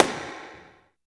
34 SNARE 2-L.wav